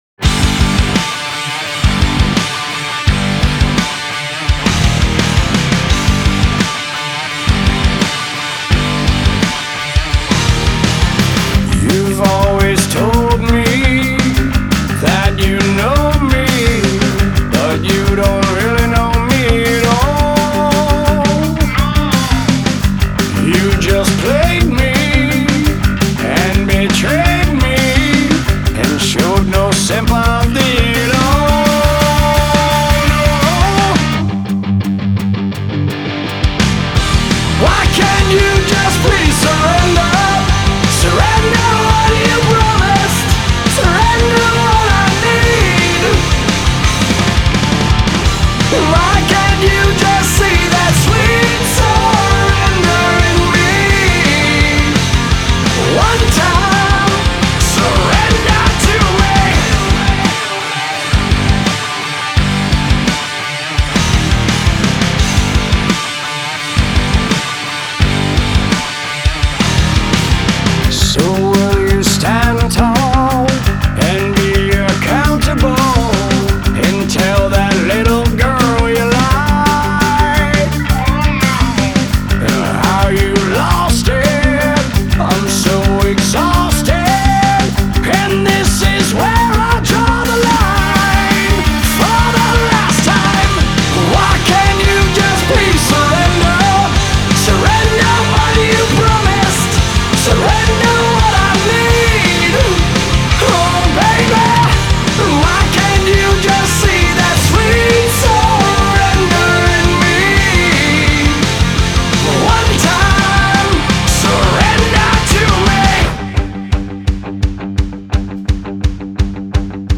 alternative metal Hard Rock